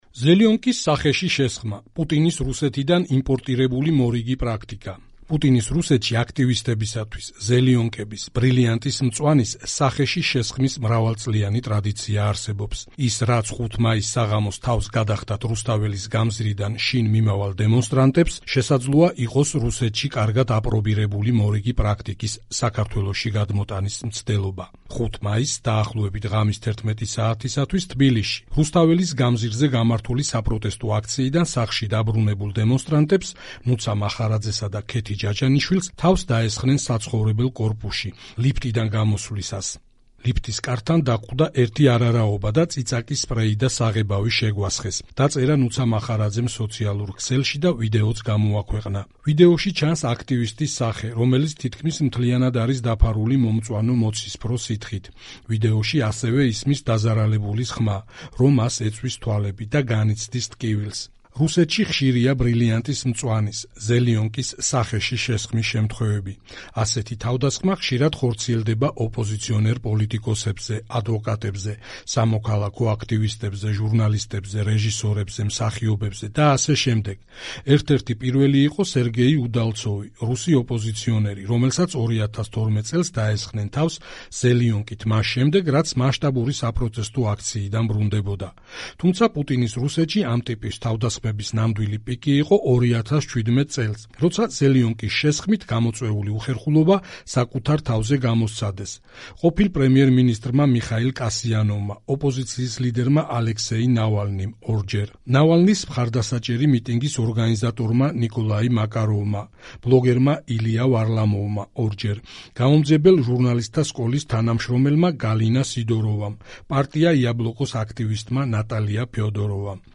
მოუსმინე სტატიას - „ზელიონკის“ სახეში შესხმა“